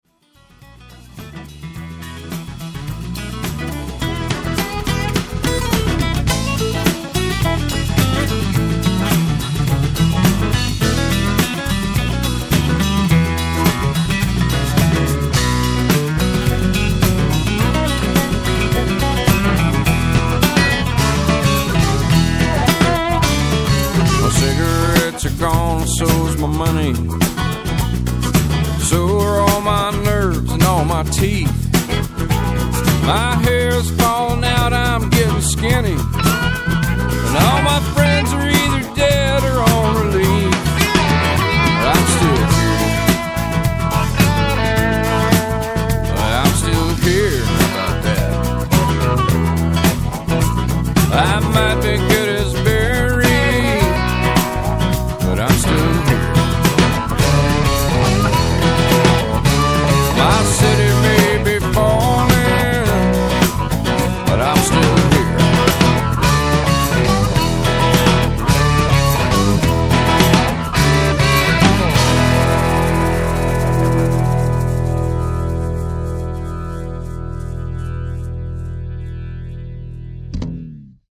THE 4th VERSE AS SUNG BY